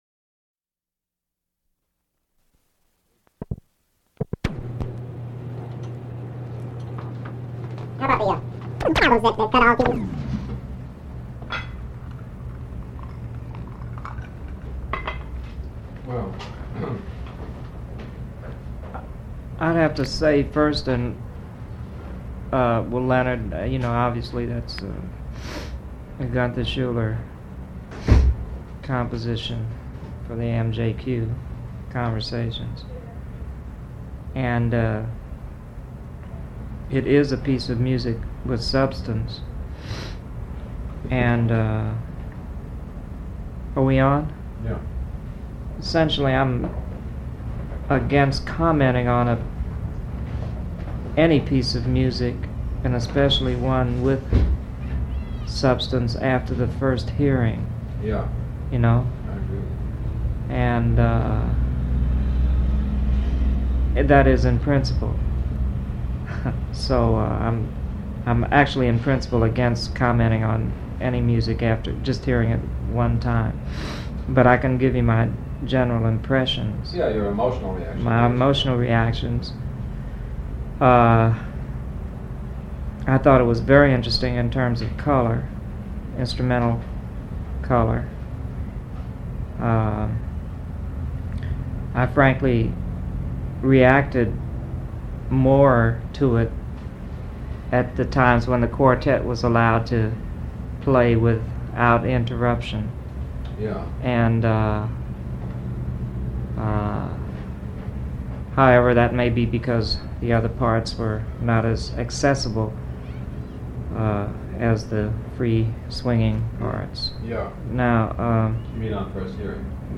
Item from Leonard Feather Collection: George Russell participates in one of Leonard Feather's blindfold tests. George Russell was an American jazz pianist.